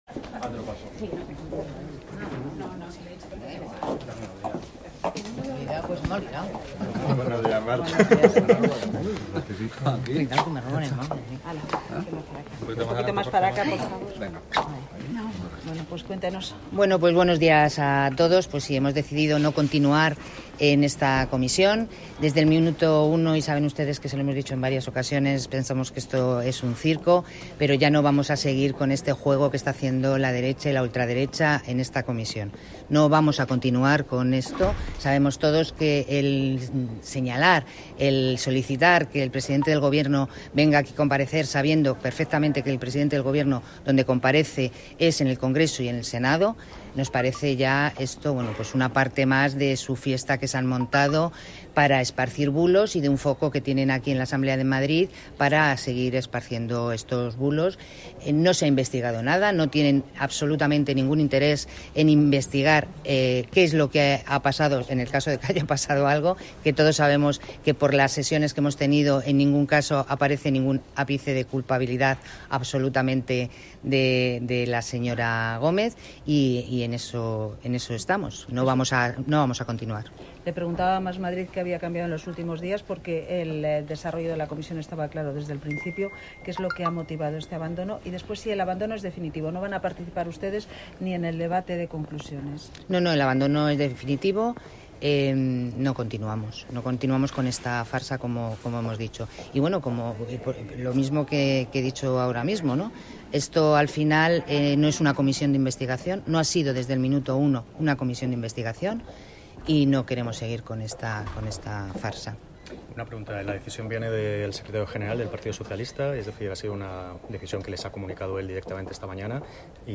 Declaraciones de Marta Bernardo, portavoz de la Comisión de Investigación sobre programas de cátedras y postgrados de la Universidad Complutense de Madrid.